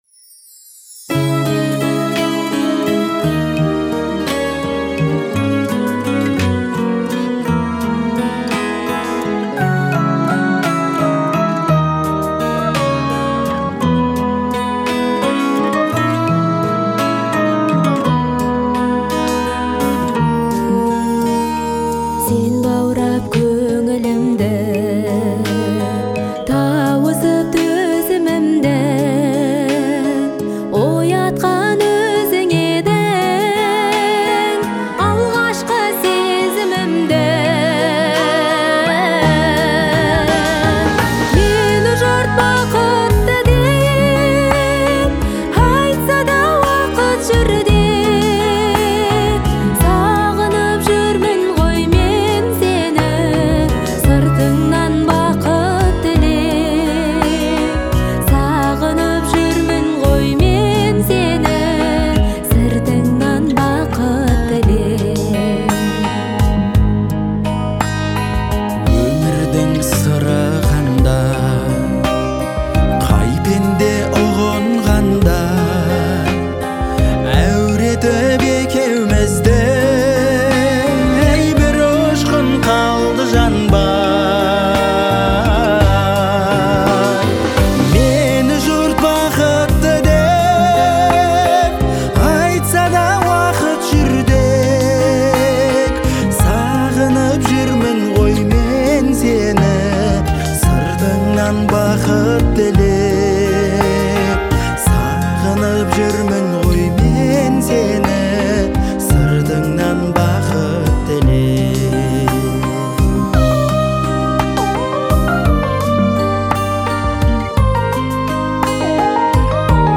это трогательный дуэт в жанре казахской поп-музыки